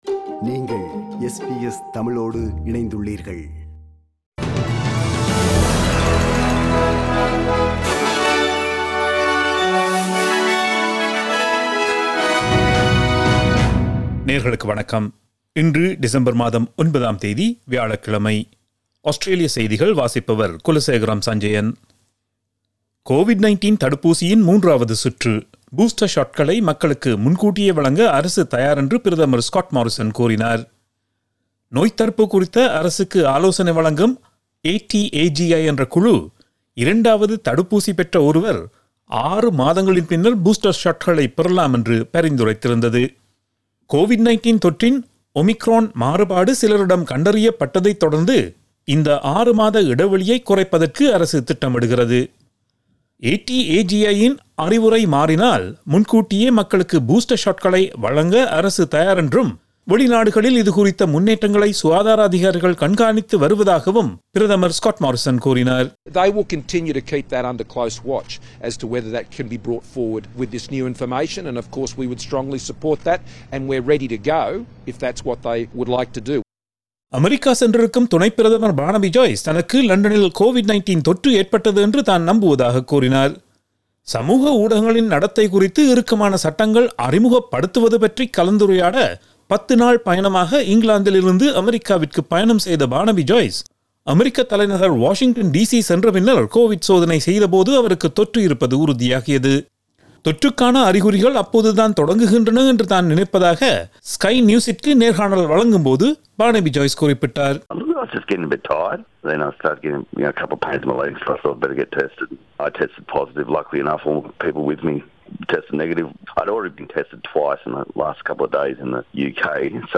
Australian news bulletin for Thursday 09 December 2021.